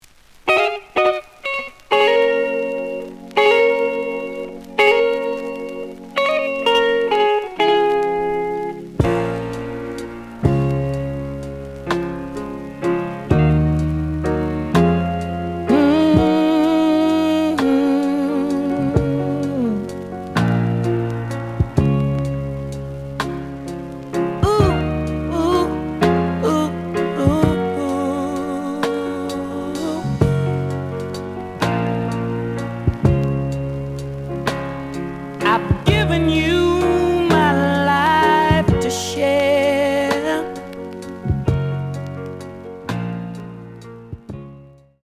The audio sample is recorded from the actual item.
●Genre: Funk, 70's Funk
Slight click noise on both sides due to a bubble.)